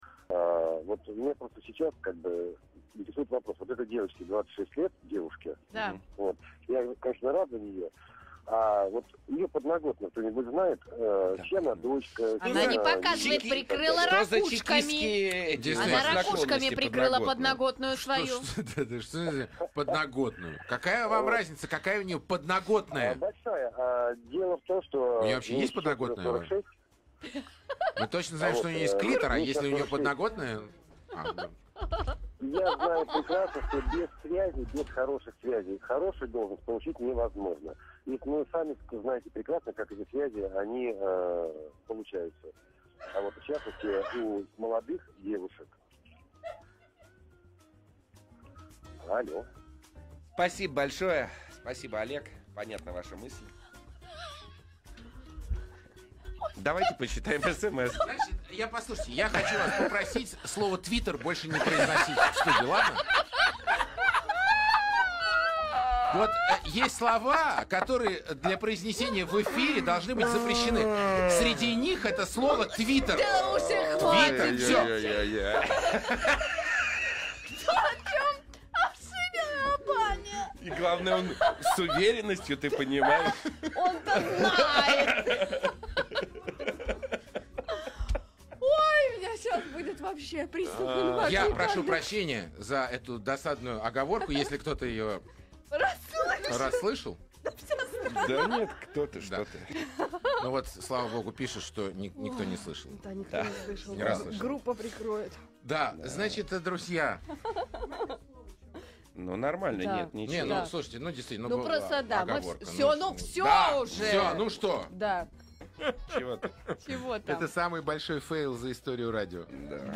оговорка радиоведущих Маяка
| Теги: истерика в эфире, ляп, Маяк, оговорка, смех, шоу Ивановы, радиостанция Маяк